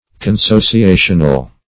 Search Result for " consociational" : The Collaborative International Dictionary of English v.0.48: Consociational \Con*so`ci*a"tion*al\, a. Of or pertaining to a consociation.